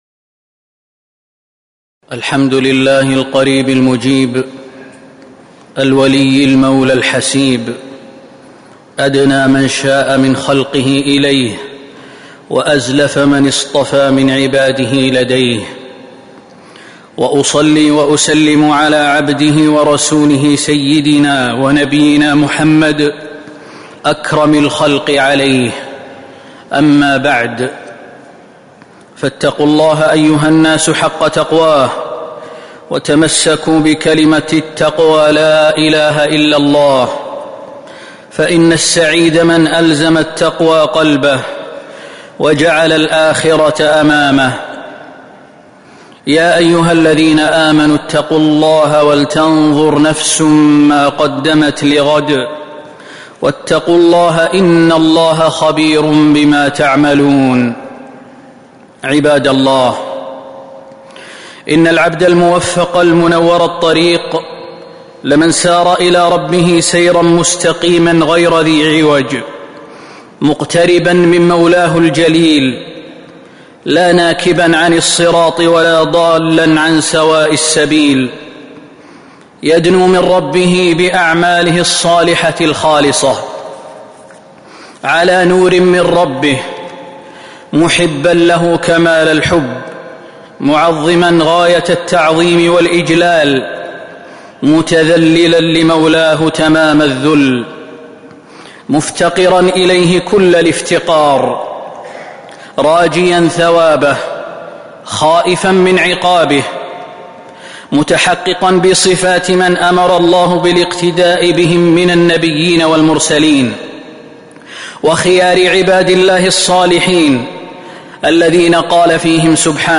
تاريخ النشر ١٨ ذو القعدة ١٤٤٦ هـ المكان: المسجد النبوي الشيخ: فضيلة الشيخ د. خالد بن سليمان المهنا فضيلة الشيخ د. خالد بن سليمان المهنا من أسباب محبة الله